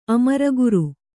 ♪ amaraguru